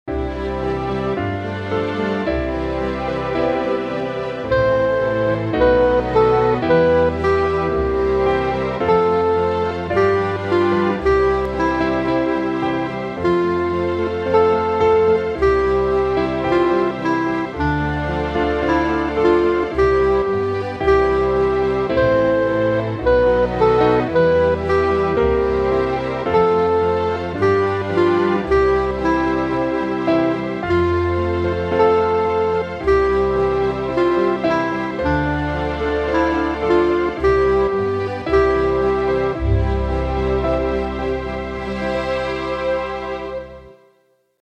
Another chant, for a baptism in this case